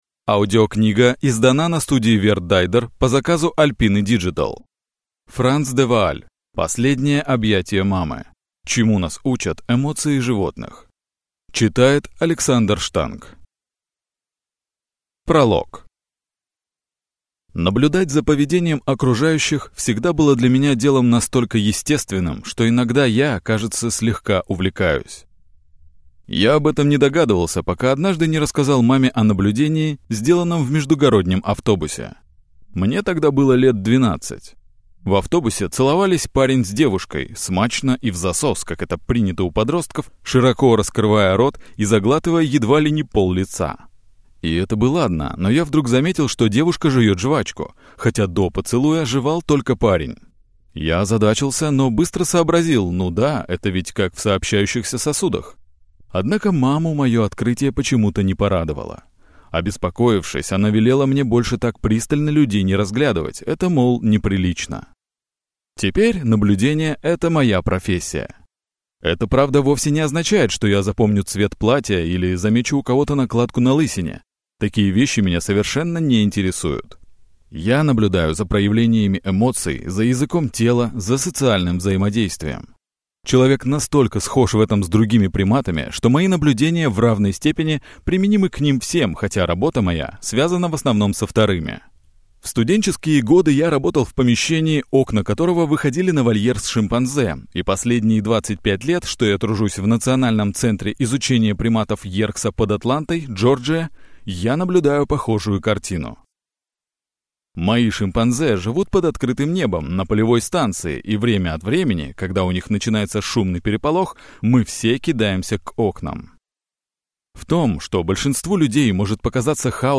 Аудиокнига Последнее объятие Мамы | Библиотека аудиокниг